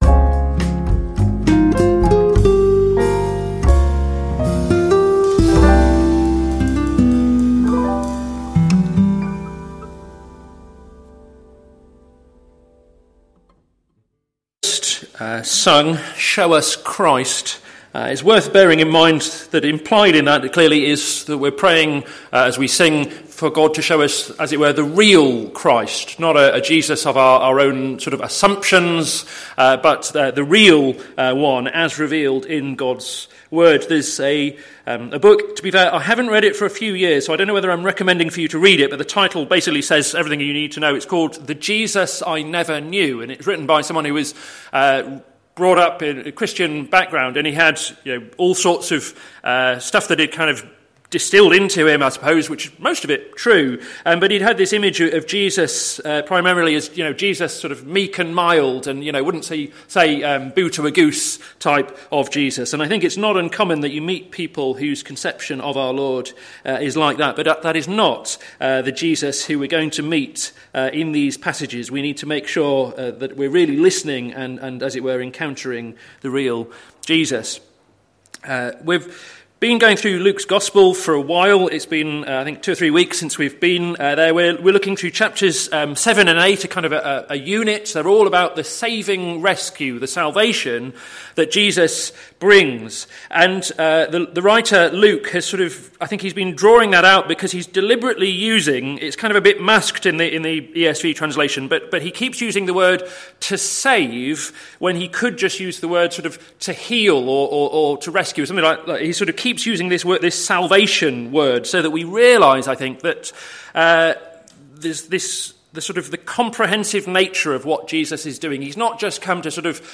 Sermon Series - To Seek and to Save the Lost - plfc (Pound Lane Free Church, Isleham, Cambridgeshire)